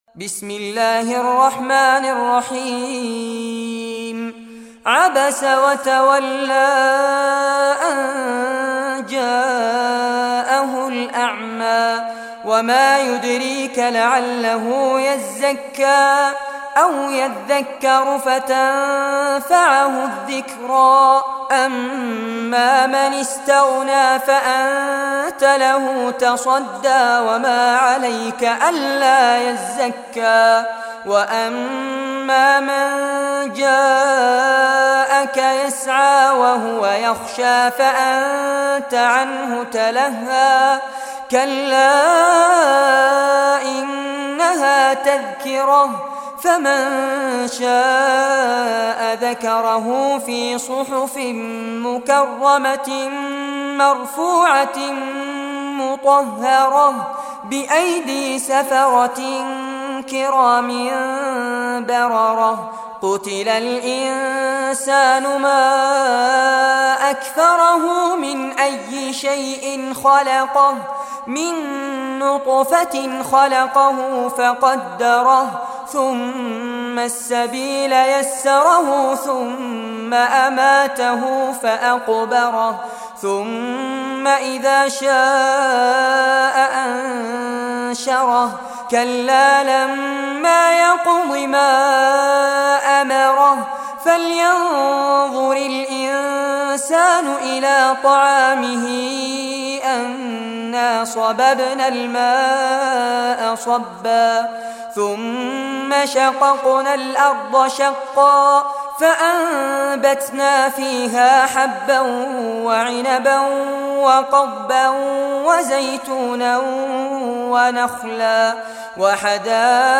Surah Abasa Recitation by Sheikh Fares Abbad
Surah Abasa, listen or play online mp3 tilawat / recitation in Arabic in the beautiful voice of Sheikh Fares Abbad.